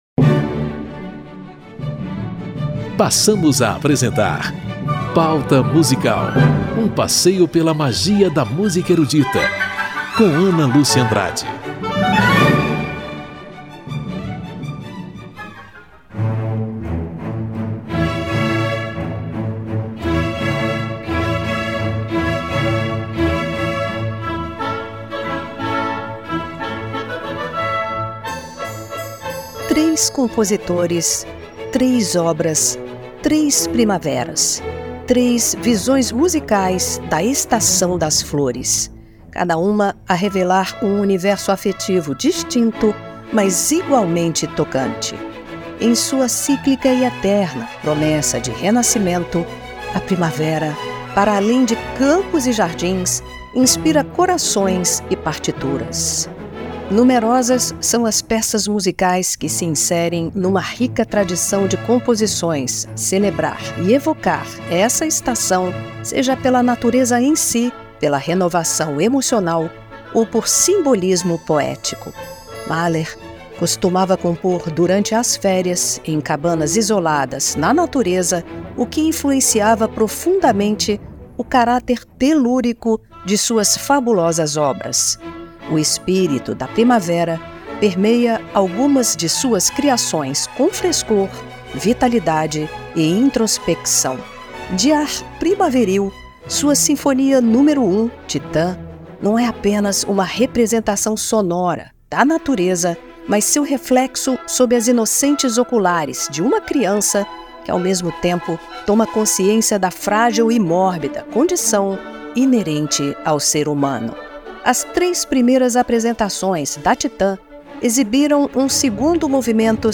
Solistas e orquestras interpretam temas primaveris da música ocidental em primaveras de Gustav Mahler, Ludwig van Beethoven e Antonio Vivaldi.